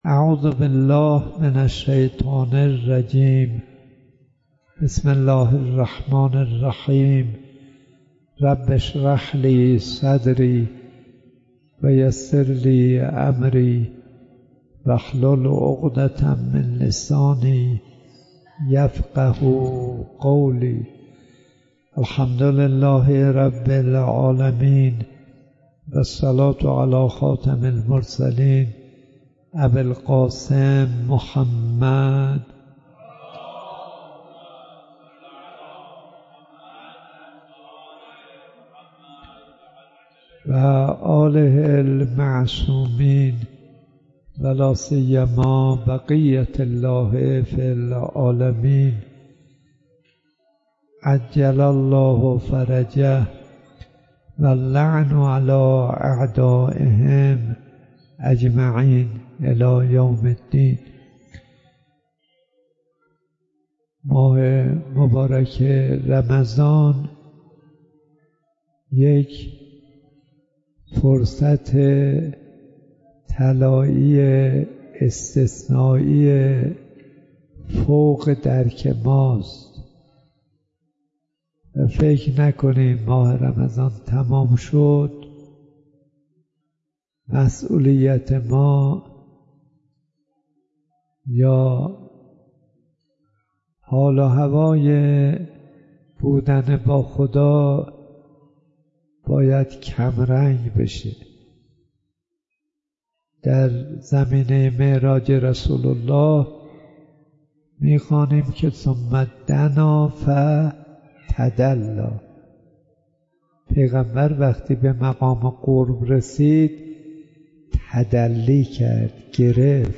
شیخ کاظم صدیقی حریم ملکوت ۲۱۰ درس اخلاق آیت الله صدیقی؛ ۰۳ اردیبهشت ۱۴۰۳ در حال لود شدن فایل های صوتی...